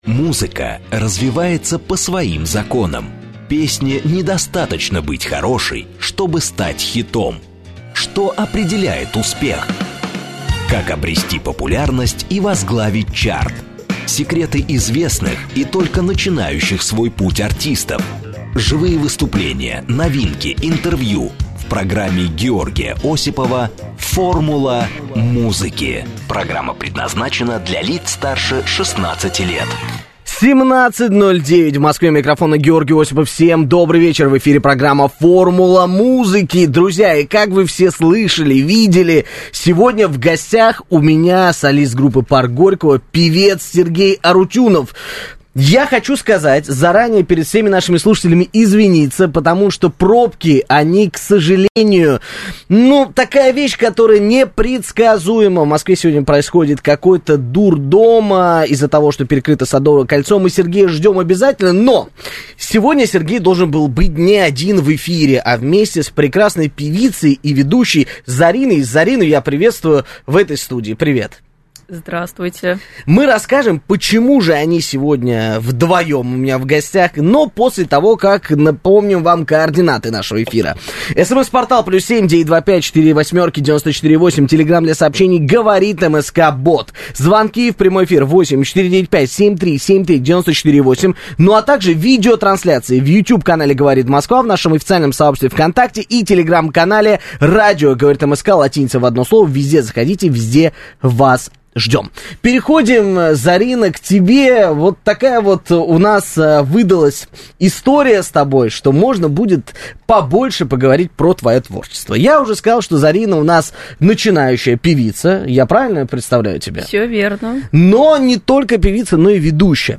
певица, бывшая солистка группы Винтаж